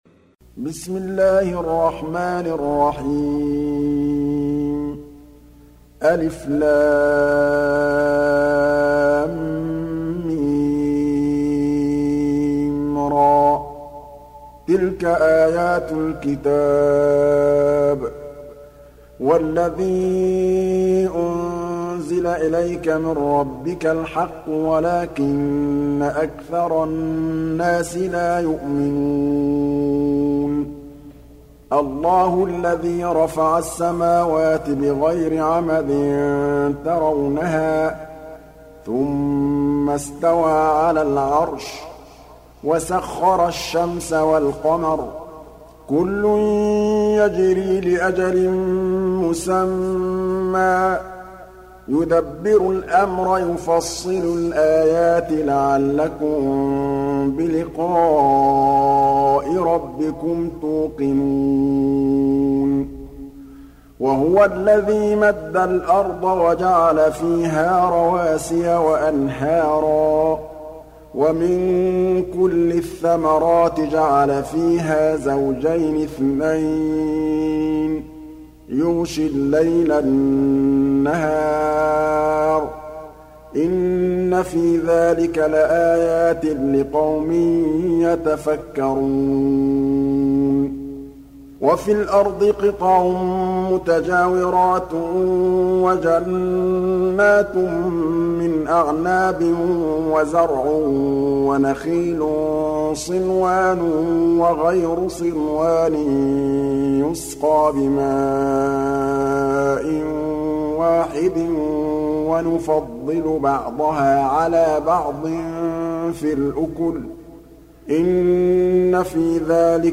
Surat Ar Rad Download mp3 Muhammad Mahmood Al Tablawi Riwayat Hafs dari Asim, Download Quran dan mendengarkan mp3 tautan langsung penuh